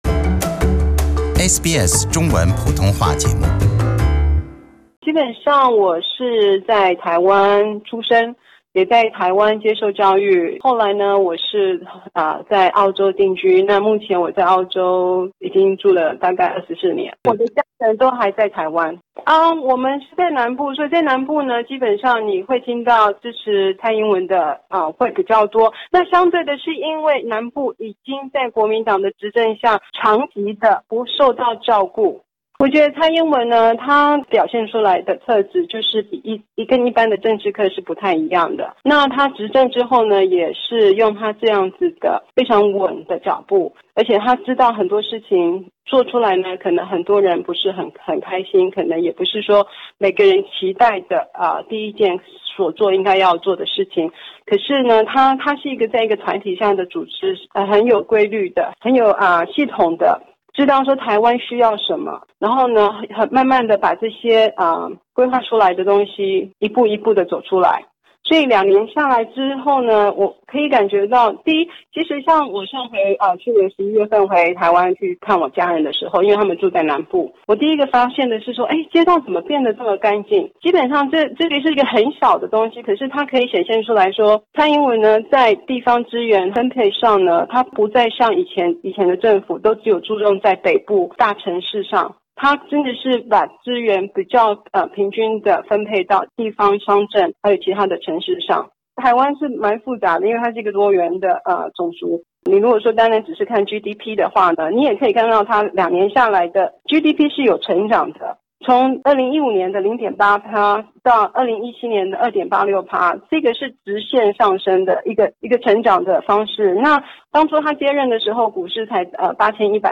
点击收听全部采访音频。